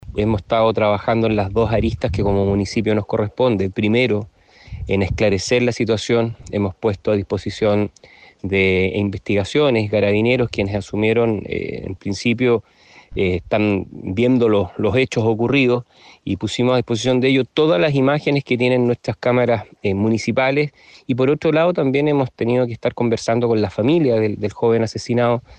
Fue el alcalde de la comuna, Rodrigo Díaz, quien destacó el trabajo cooperativo con las policías, así como también el apoyo que le han entregado a la familia de la víctima.